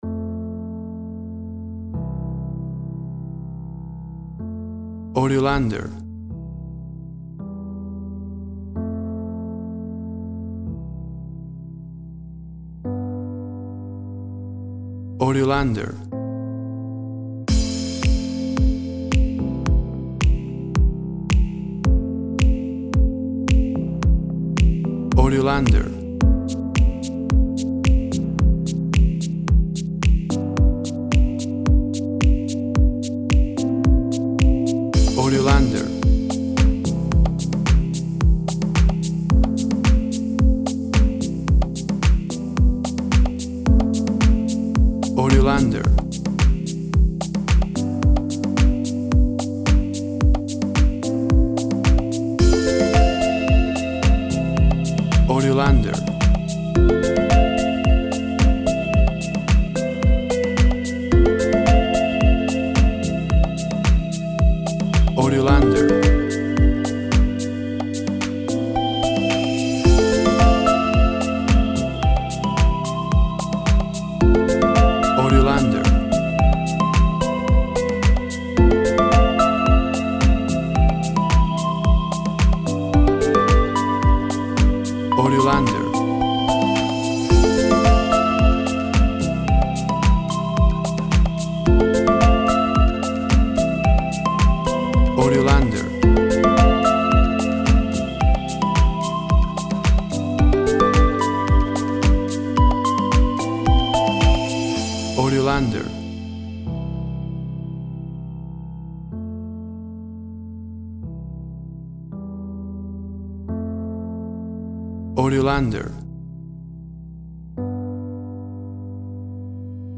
Tempo (BPM) 110